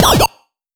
Robot Talk 003.wav